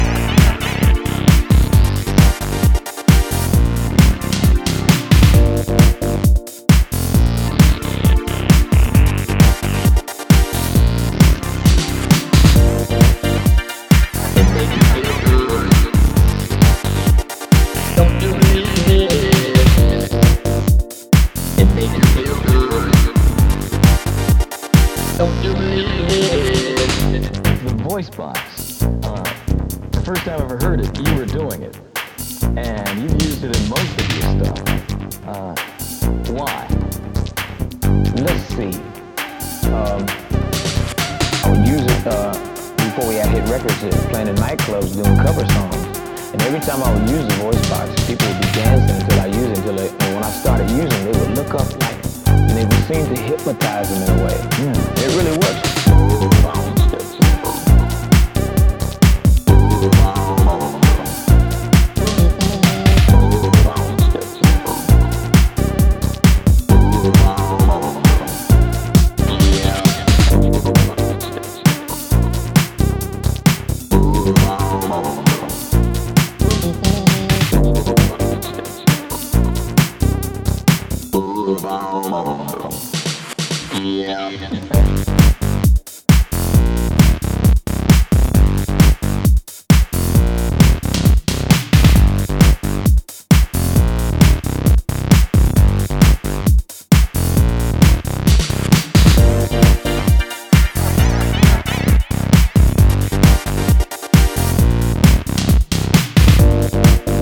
starting the EP with a funky tone